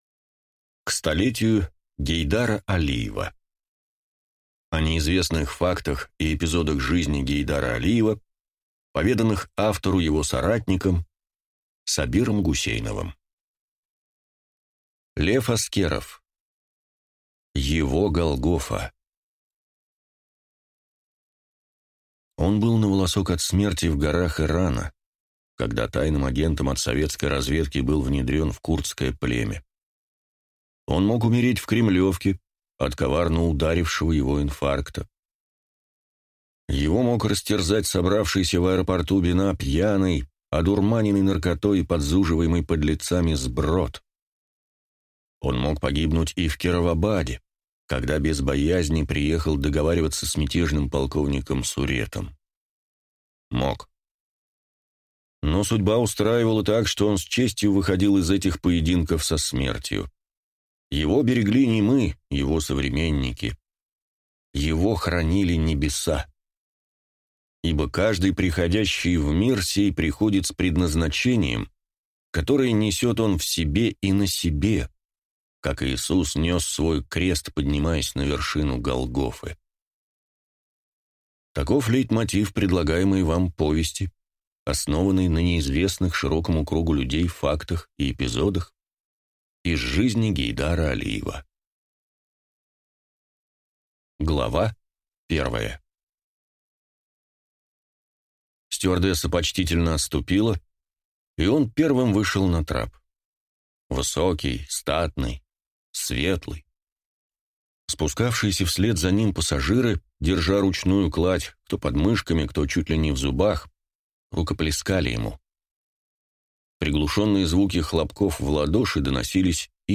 Аудиокнига Его Голгофа | Библиотека аудиокниг
Прослушать и бесплатно скачать фрагмент аудиокниги